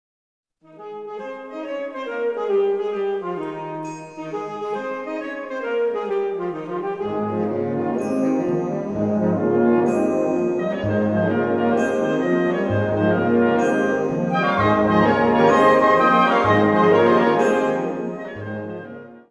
Category Concert/wind/brass band
Subcategory Suite
Instrumentation Ha (concert/wind band)